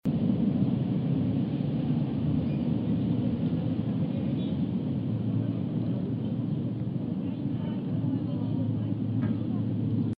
Pulse en cada imagen para escuchar un ruido de tráfico típico, atenuado por la correspondiente configuración de aislamiento.
b) ventana doble, vidrio 4 mm, cámara de aire 12 mm.
Ruido ambiental atenuado por la configuración de aislamiento fachada de ladrillo y ventana doble, vidrio 4mm, cámara 12 mm.